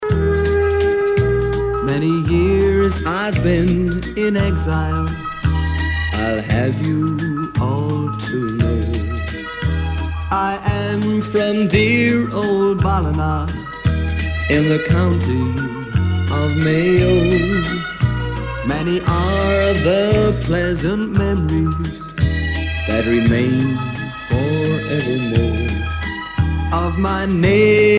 danse : valse
Pièce musicale éditée